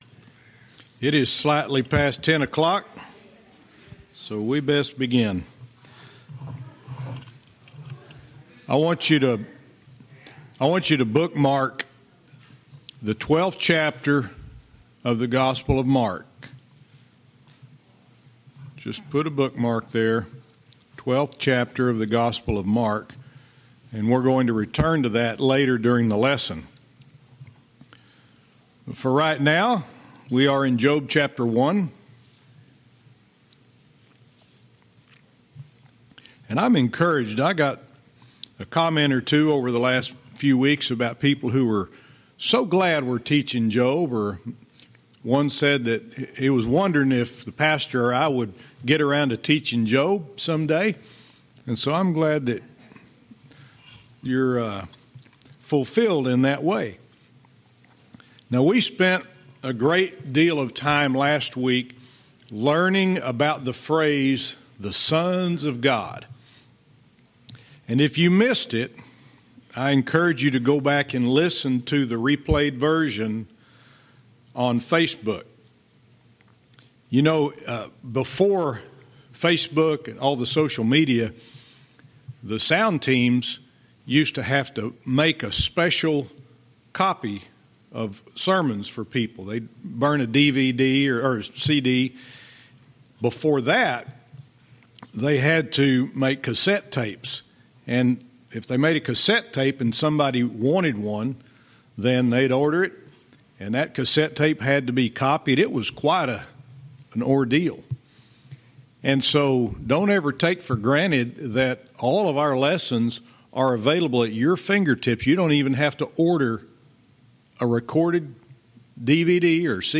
Verse by verse teaching - Job 1:9-11